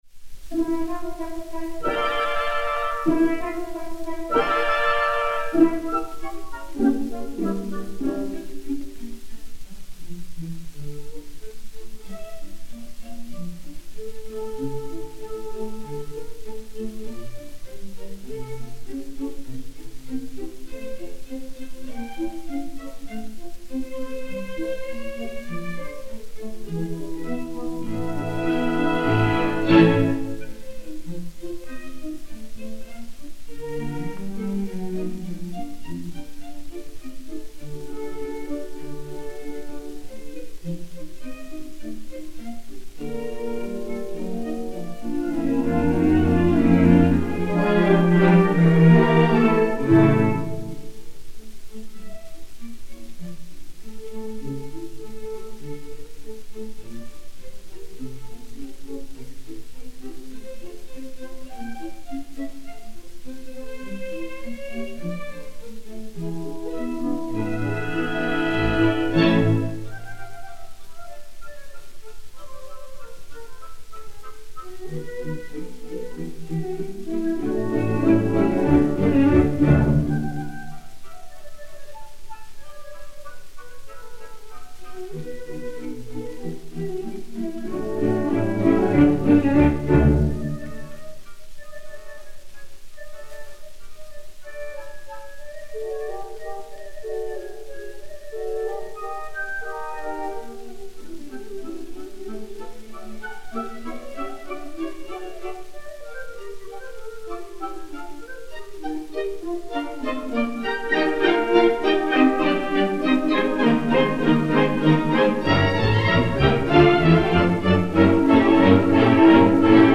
Andantino
London Symphony Orchestra dir Piero Coppola